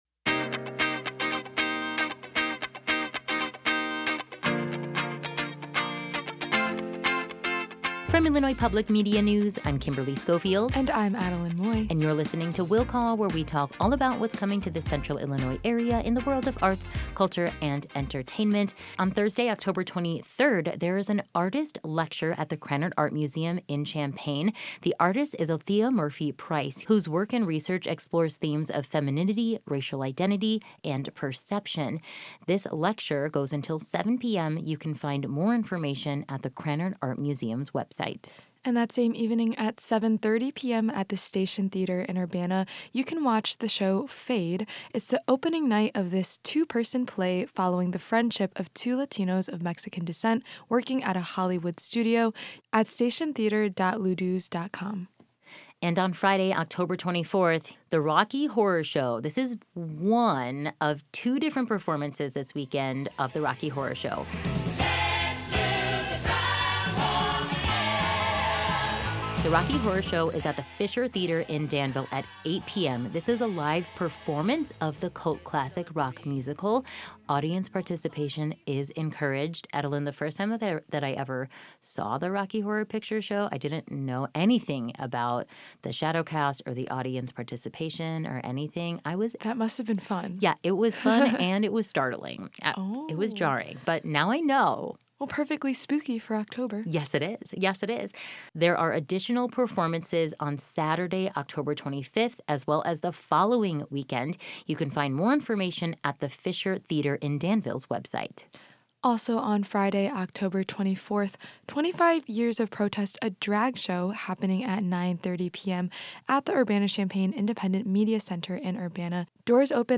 talk about weekend events on IPM News AM 580 and FM 90.9